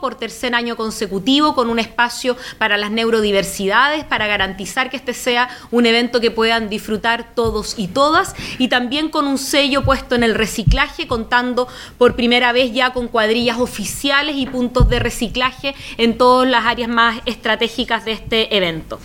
La alcaldesa Carla Amtmann destacó que nuevamente contarán con un espacio inclusivo y con foco en el cuidado del medio ambiente.